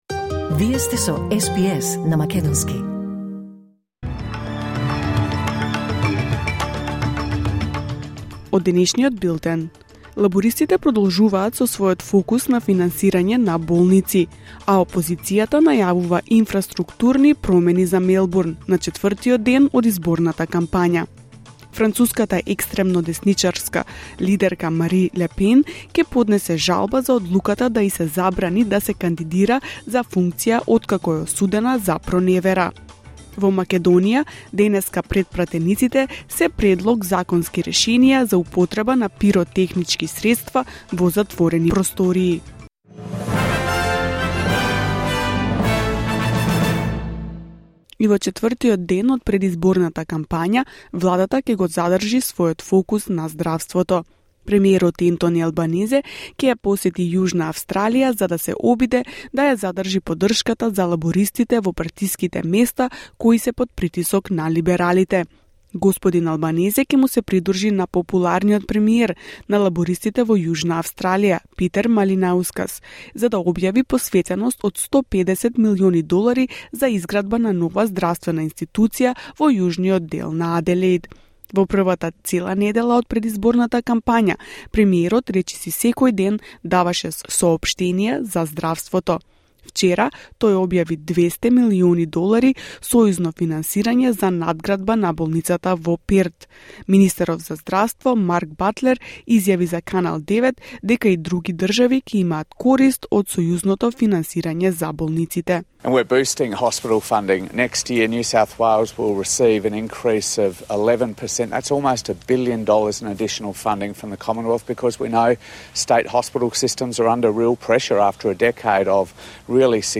Вести на СБС на македонски 1 април 2025